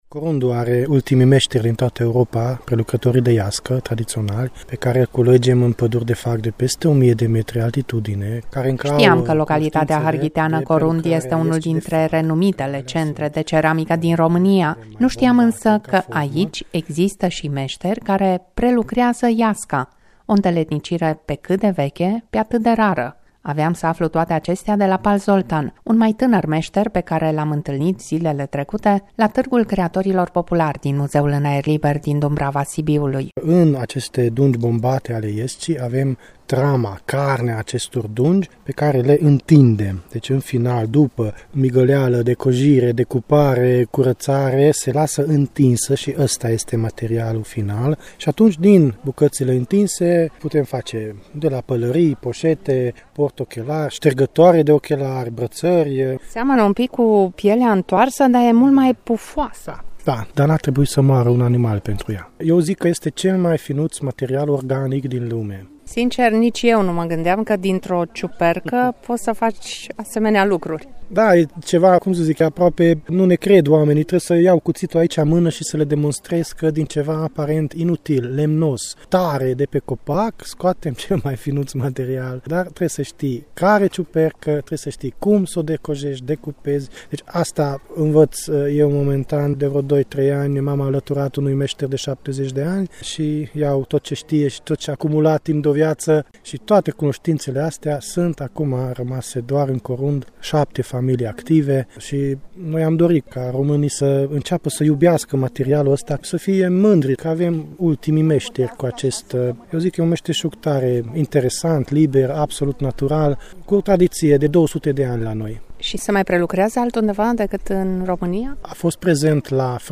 Între 13 și 15 august am participat cu un umil stand la Târgul Creatorilor Populari, ediția a XXXVIII-a în incinta Muzeului Astra în aer liber din Dumbrava Sibiului.